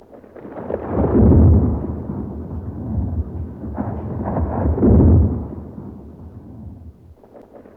Index of /90_sSampleCDs/E-MU Producer Series Vol. 3 – Hollywood Sound Effects/Ambient Sounds/Rolling Thunder